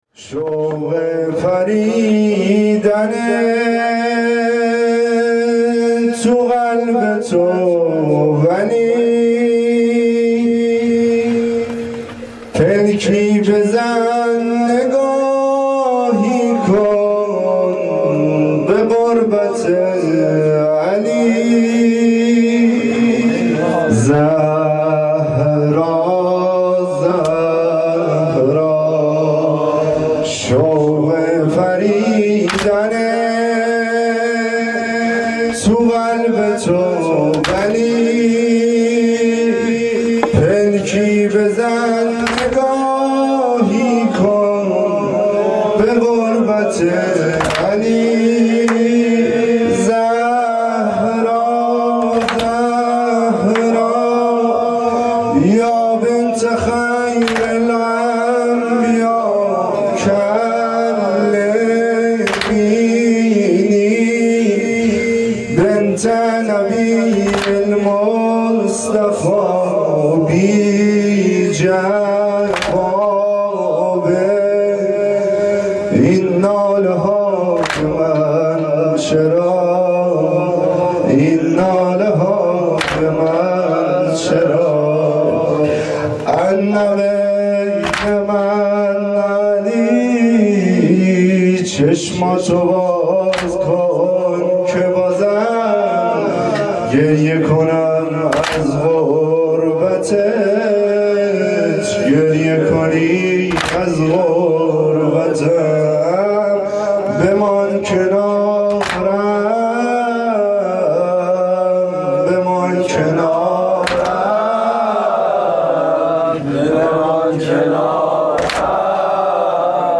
نوای فاطمیه, مداحی فاطمیه